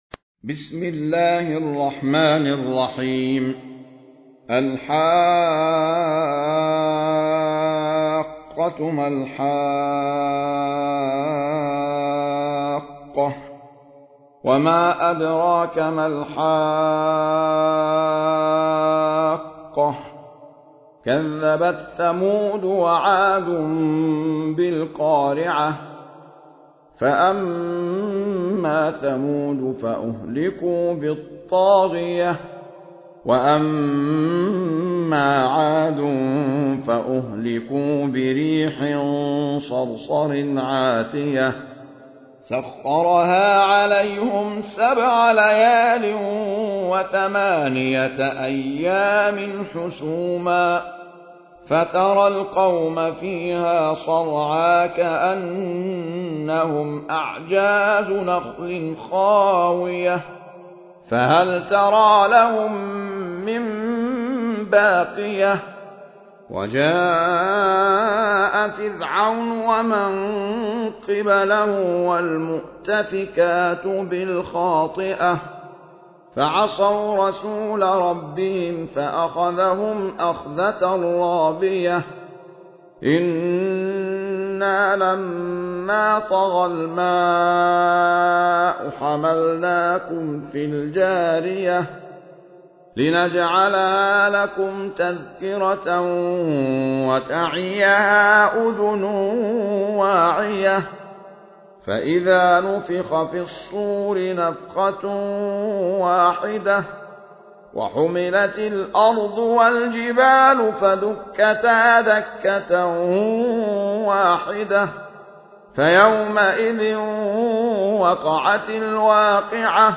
المصحف المرتل - روح عن يعقوب الحضرمي